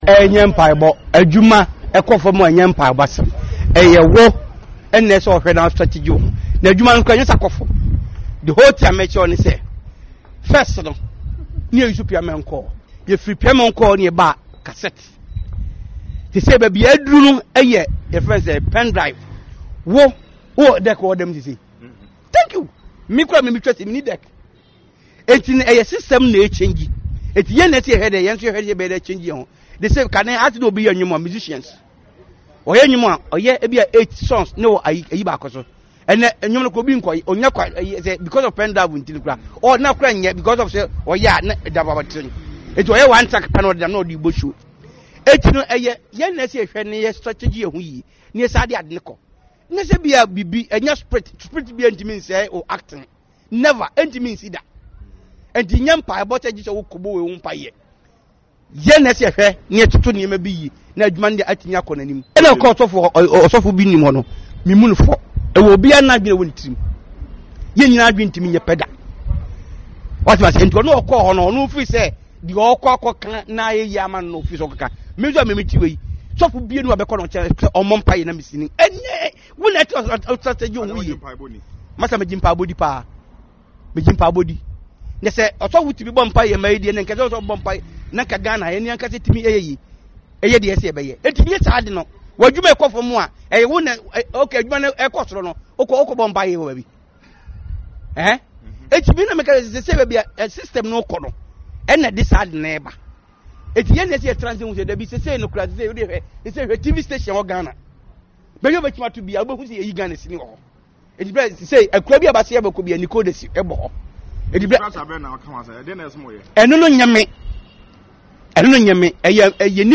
Meanwhile, Akrobeto, speaking on Adom FM’s Entertainment Hall insisted that reviving the movie industry is about emulating the western culture and not seeking favours from pastors.
Akrobeto-on-the-Kumawood-industry.mp3